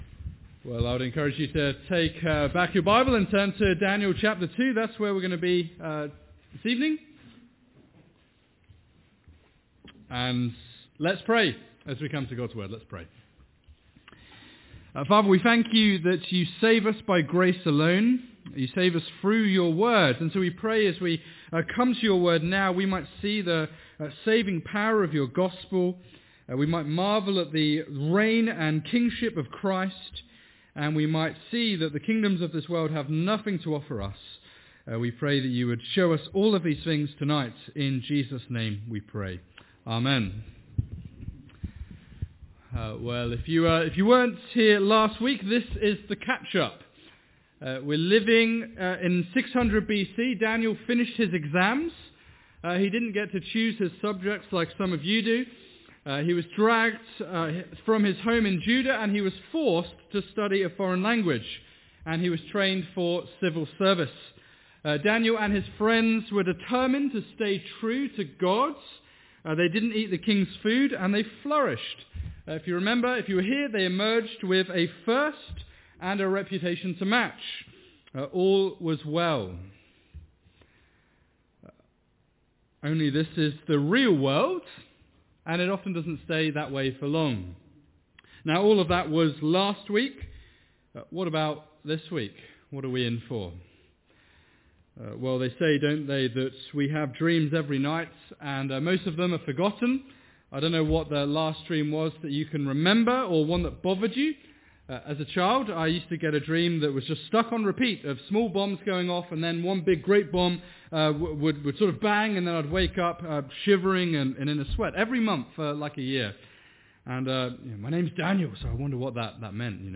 Evening Sermon